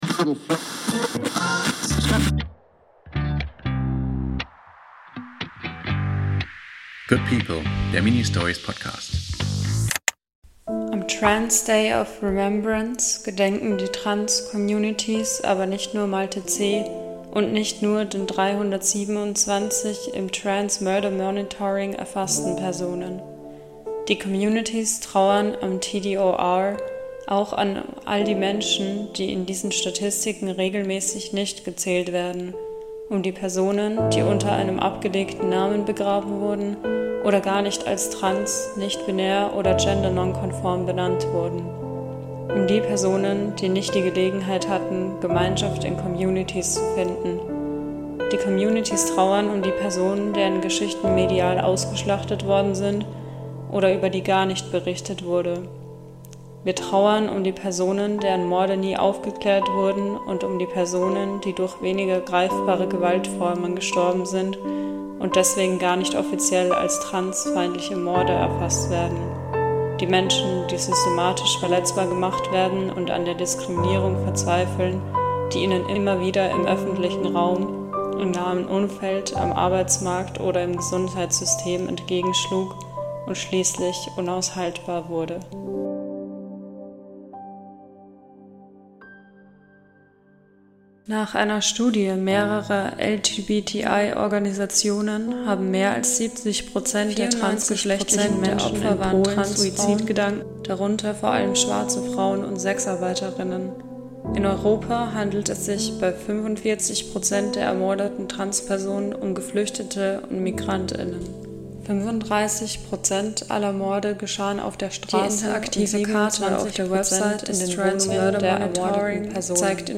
Soundcollagierend gehen wir auf den ersten CSD in Hildesheim, wir lesen Statistiken zu transfeindlicher Gewalt, wie immer weinend und dann chanten wir ein Gedicht von ALOK, um uns zu reminden, wie magical und powerful wir trans* people sind.